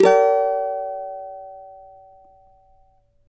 CAVA G#MN  D.wav